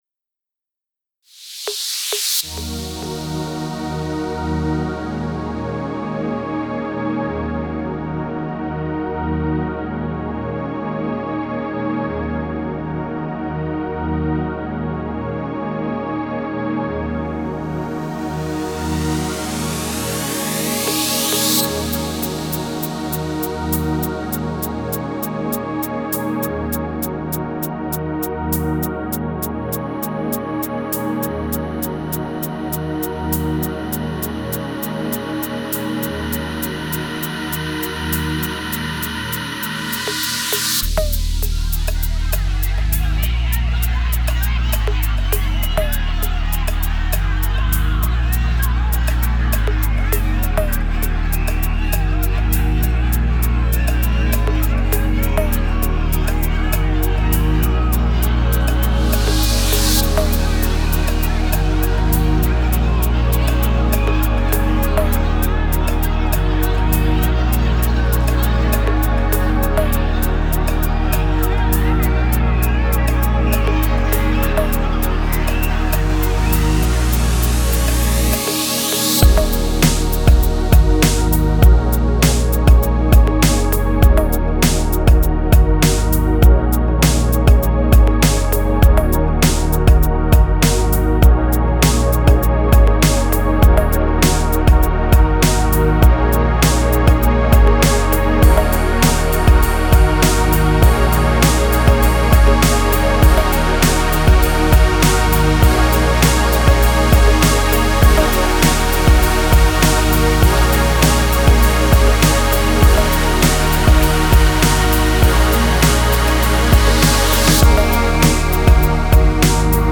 Стиль: Chillout/Lounge / Ambient/Downtempo